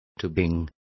Complete with pronunciation of the translation of tubing.